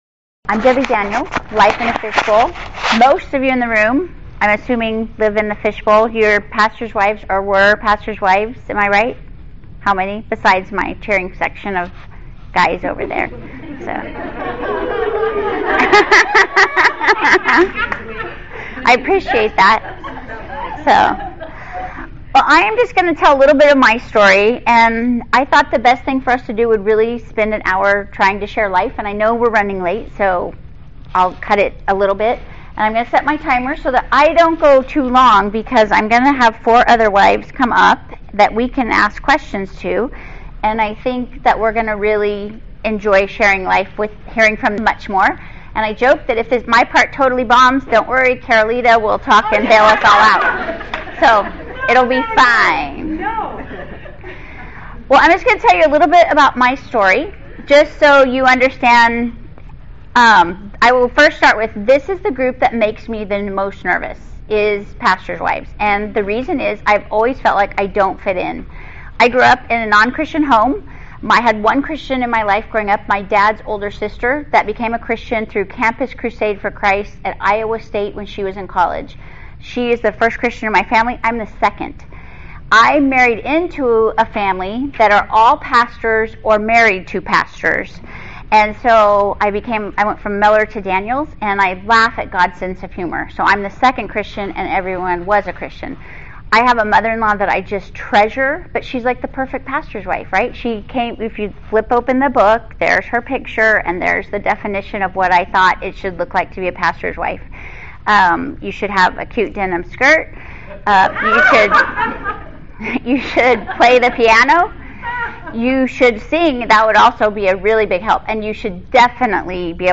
A panel of seasoned pastors’ wives will close the session with a question and answer time.